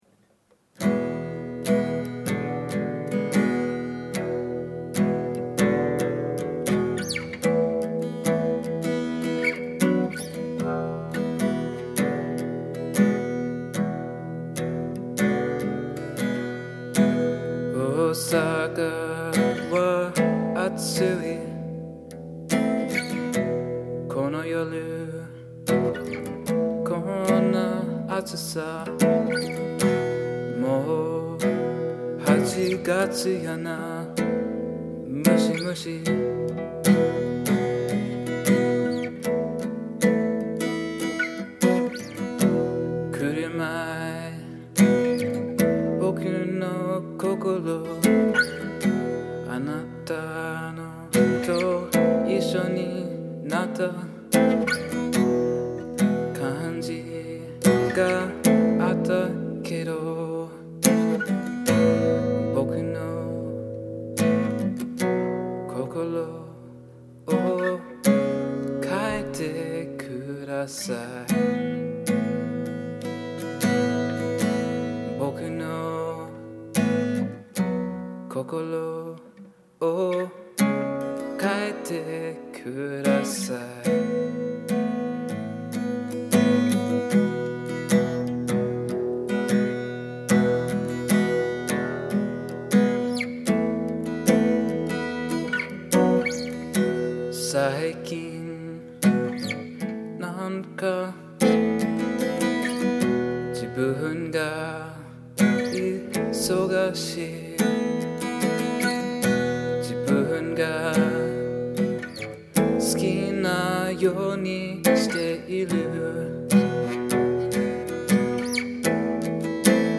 Originally, this was a guitar-based song.